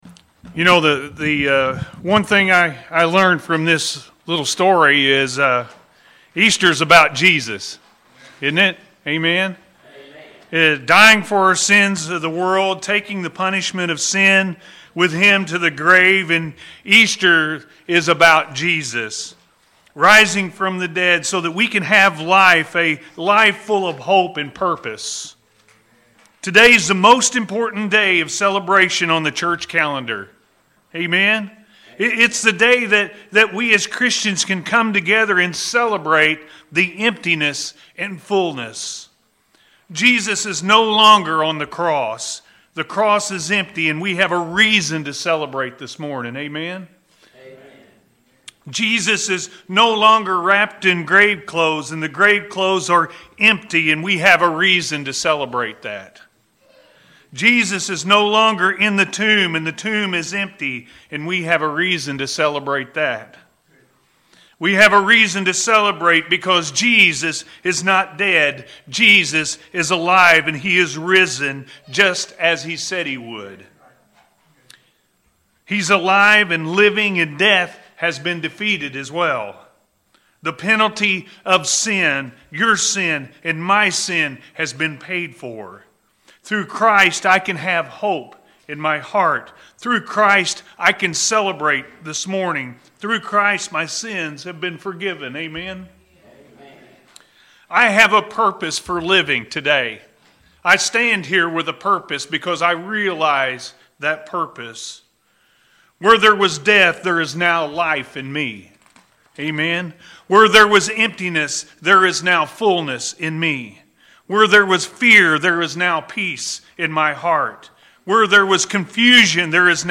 Easter Is All About Jesus-A.M. Service – Anna First Church of the Nazarene